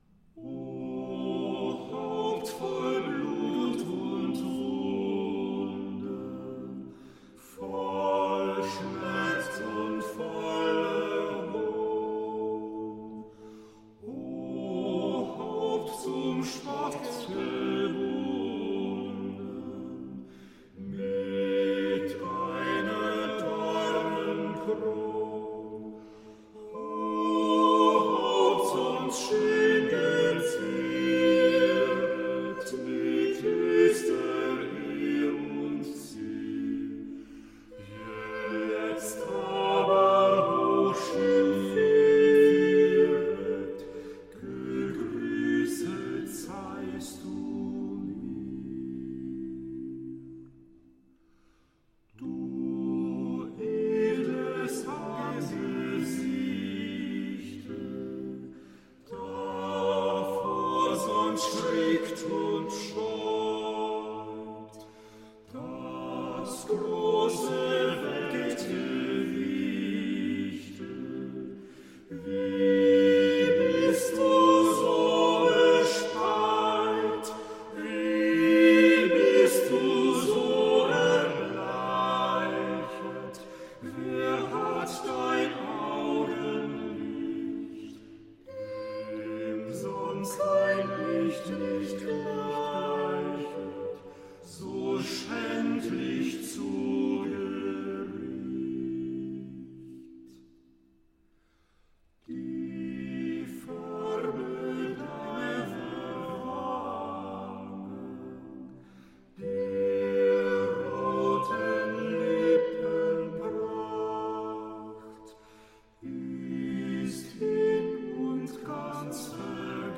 In de harmonieleer wordt meestal uitgegaan van een zetting voor vierstemmig gemengd koor.
Hans Leo Hassler, O Haupt voll Blut und Wunden, vierstemmige koraalzetting van J. Crueger.
Uitgevoerd door Ensemble Stimmwerck (Youtube)